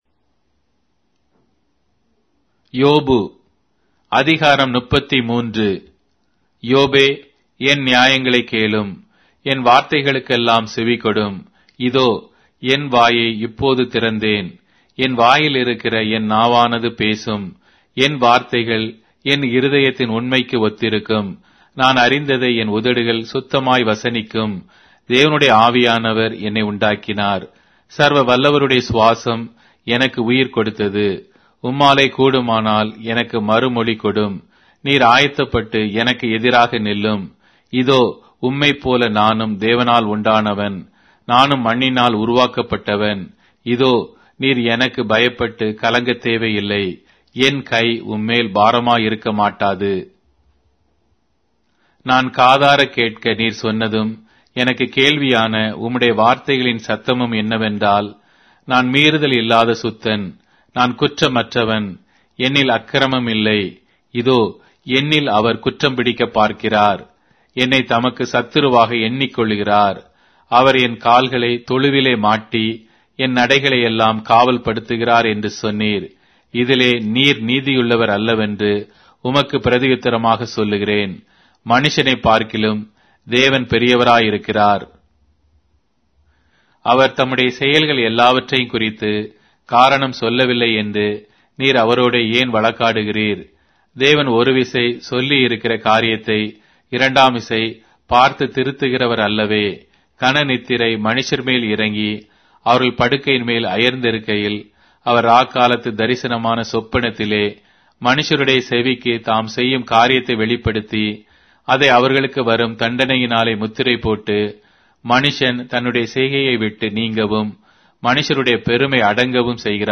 Tamil Audio Bible - Job 34 in Nlv bible version